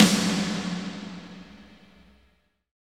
Index of /90_sSampleCDs/Roland - Rhythm Section/KIT_Drum Kits 8/KIT_Reverb Kit
SNR VERBY03R.wav